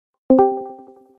Sound Effects
Discord Join Call Quiet Not Loud I Promise